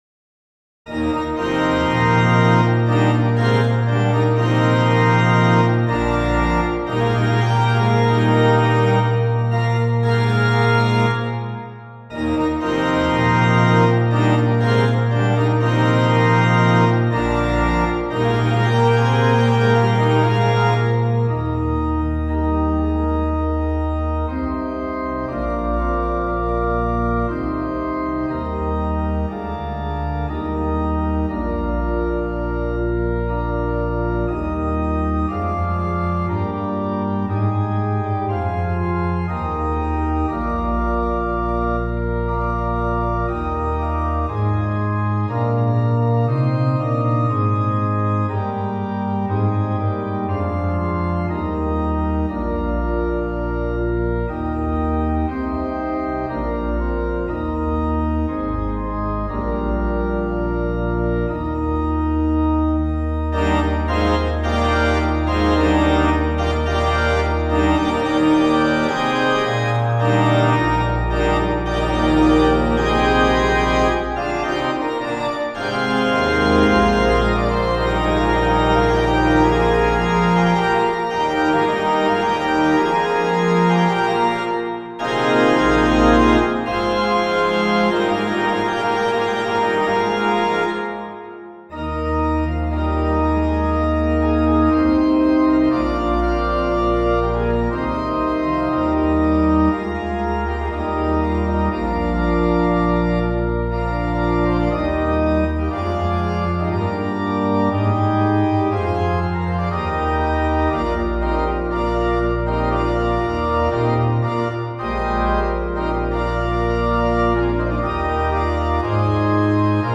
for organ
This little instrumental essay then is a small step on the long journey of the tune across centuries and various practices.
7 pages, circa 5' 45" an MP3 demo is here: